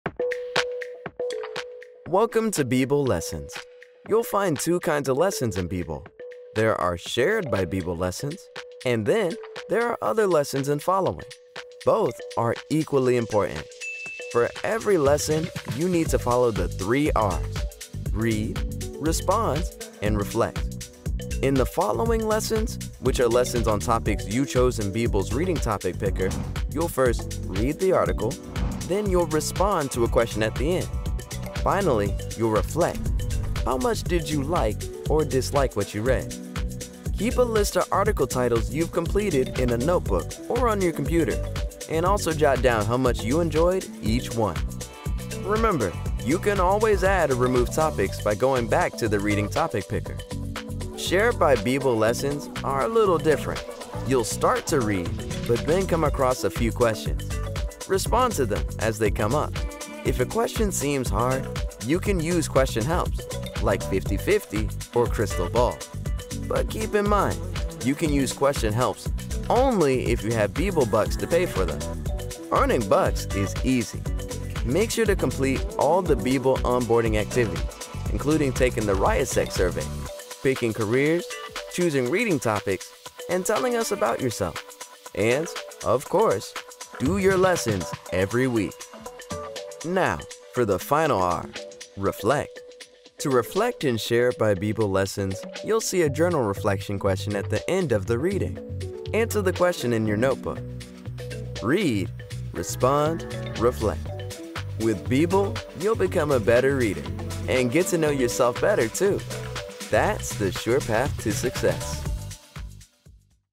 Engels (Amerikaans)
Jong, Stedelijk, Stoer, Veelzijdig, Vriendelijk
Explainer